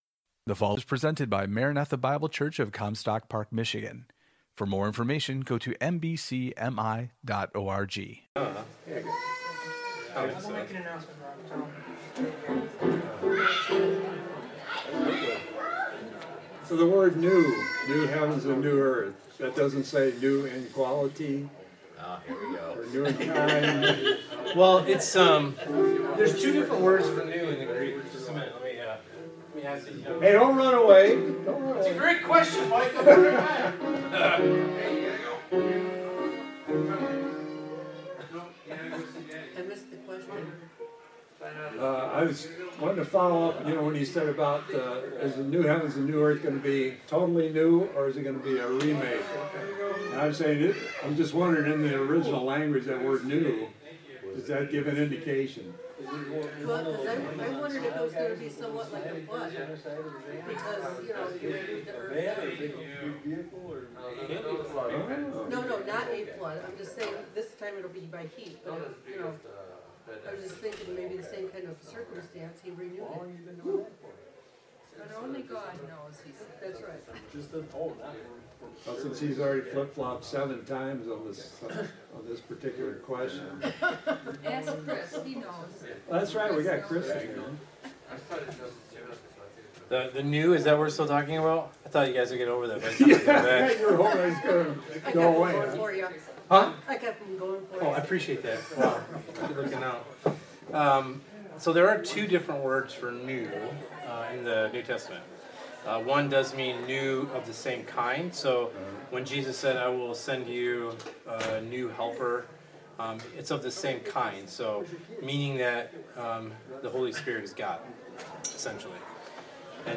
Equipping Hour – Sermon Discussion